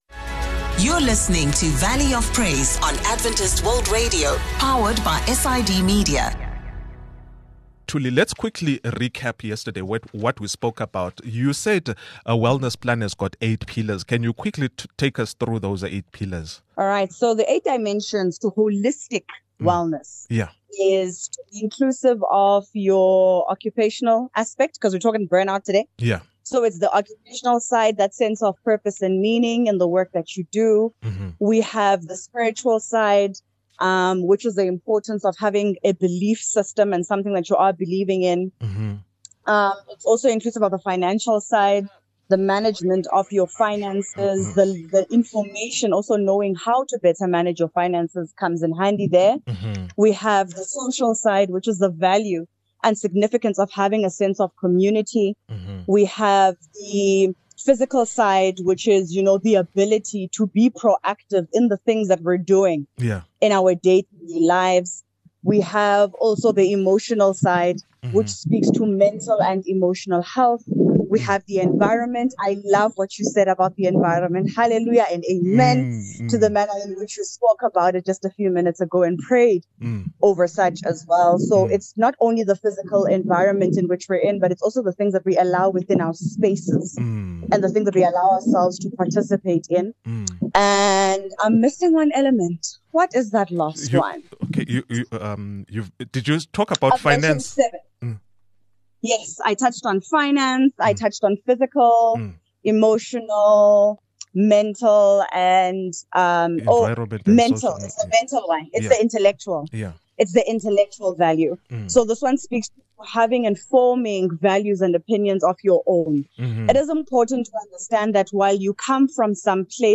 From setting achievable goals to balancing physical, mental, and spiritual health, our guest shares actionable insights and strategies to help you thrive in the year ahead. Whether you're looking to boost energy, reduce stress, or cultivate a more fulfilling life, this conversation is packed with tips to guide you toward holistic well-being.